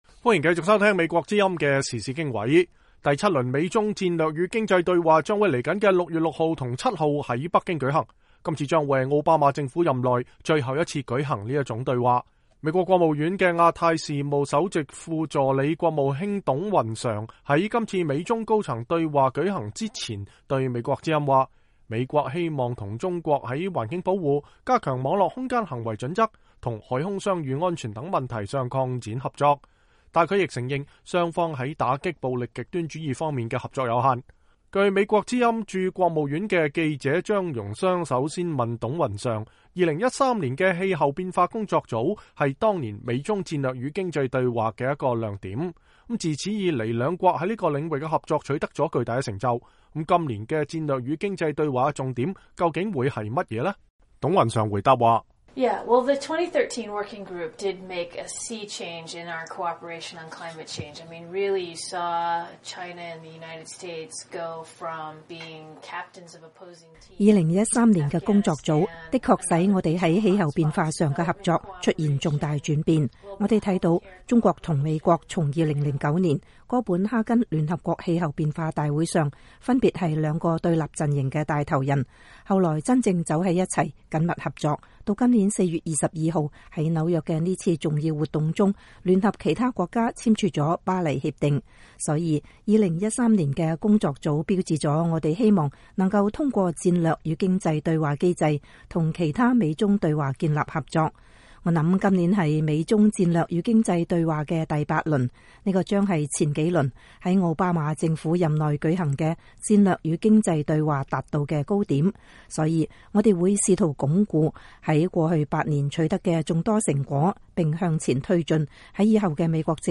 專訪美國官員 預覽第八輪美中對話